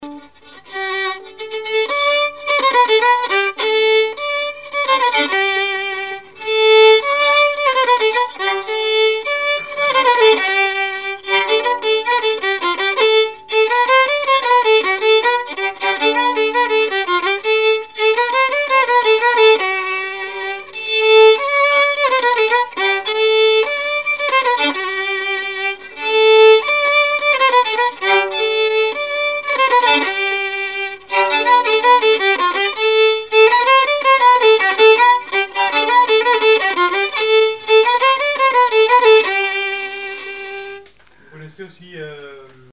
Polka
Chants brefs - A danser
danse : polka piquée
Répertoire musical au violon
Pièce musicale inédite